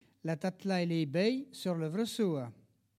Collectif atelier de patois
Locution